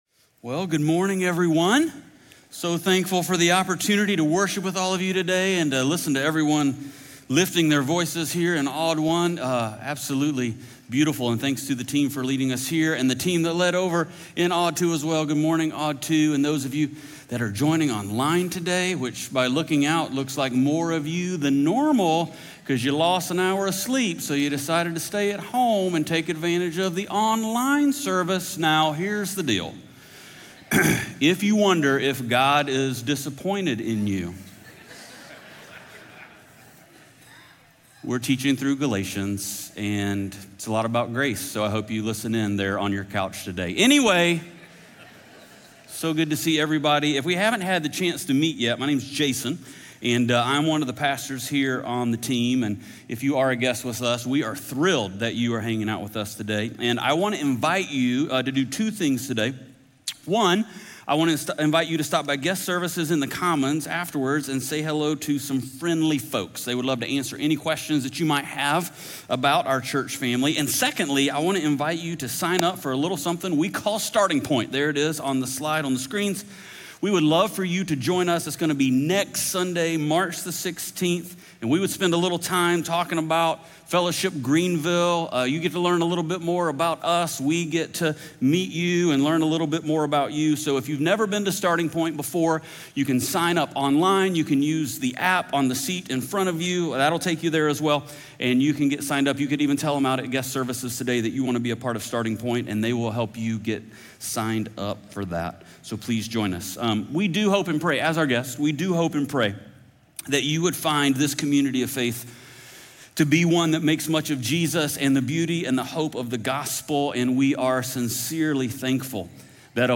Galatians 6:9-10 Audio Sermon